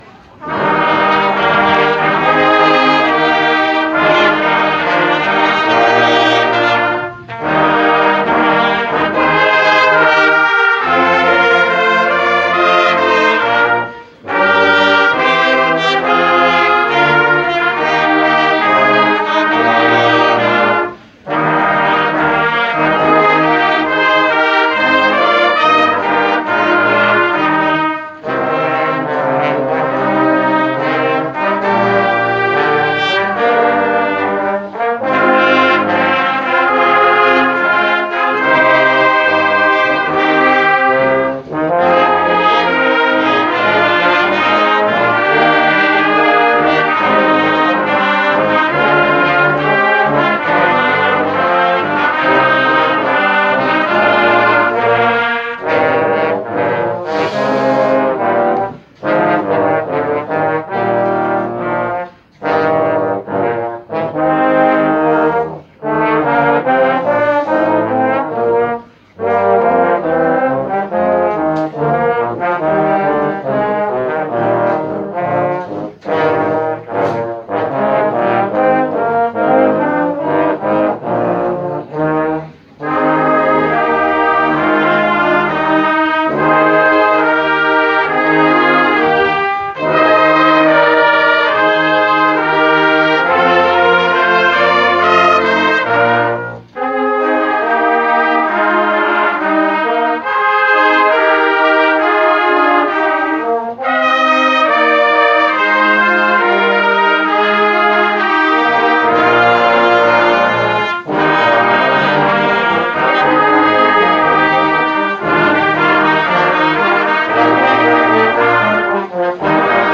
250810_Ökumen.GoDi_Was.stimmt.nicht.mit.der.Welt.mp3